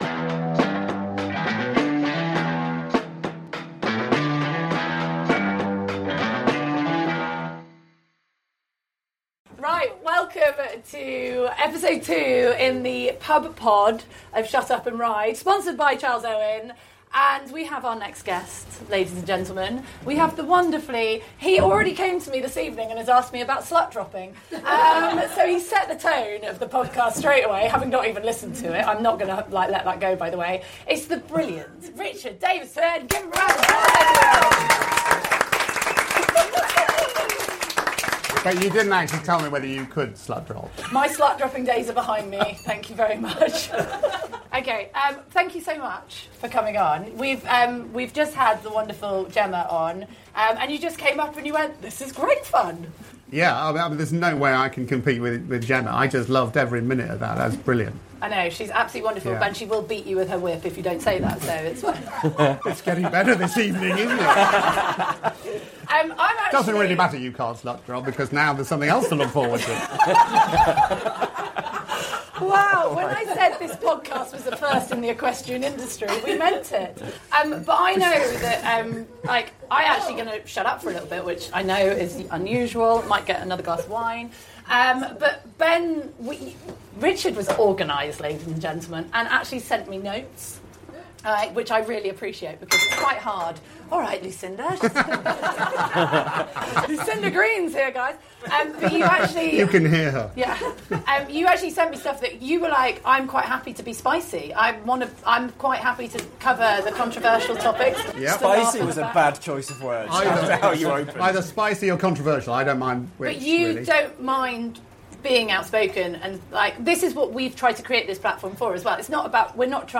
Expect honest opinions, lighthearted banter, and a few surprise revelations along the way.